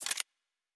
pgs/Assets/Audio/Sci-Fi Sounds/Weapons/Weapon 04 Foley 3.wav
Weapon 04 Foley 3.wav